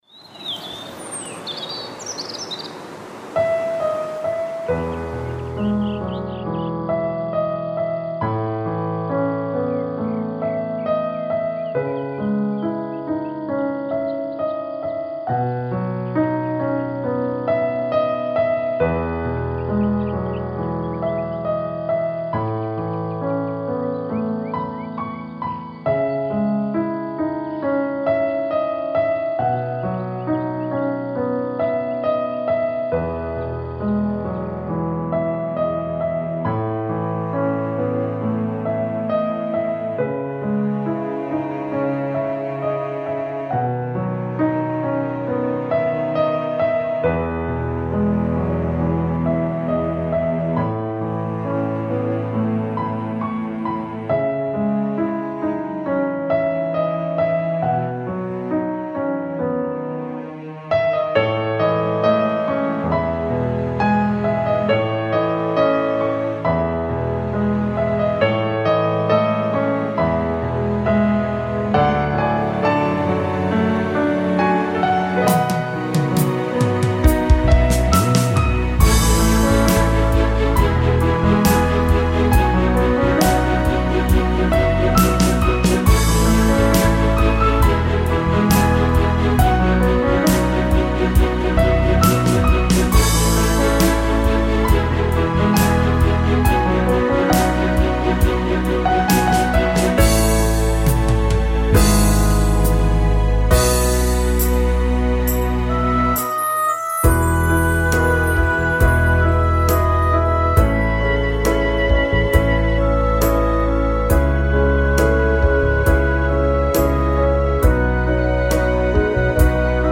Žánr: Jazz/Blues